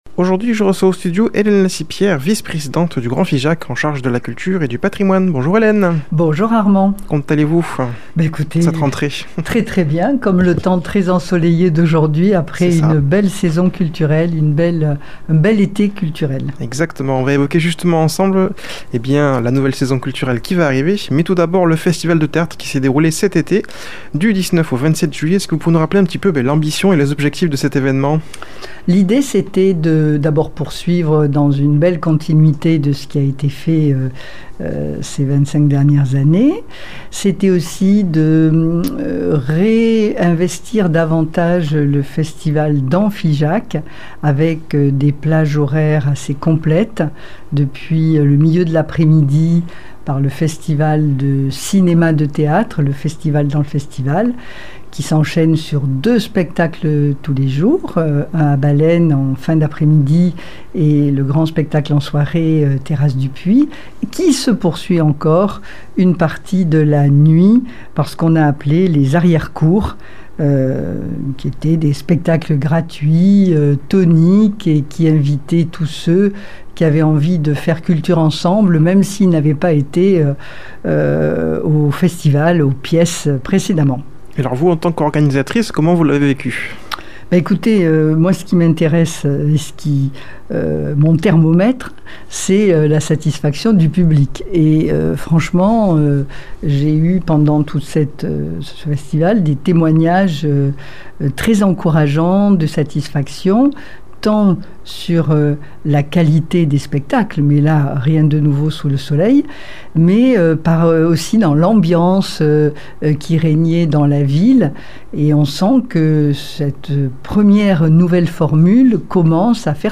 a comme invitée au studio Hélène Lacipière vice présidente du Grand Figeac, en charge de la culture et du patrimoine. Elle vient faire un bilan du festival de théâtre de Figeac qui s'est tenu en Juillet et nous parler de la nouvelle saison culturelle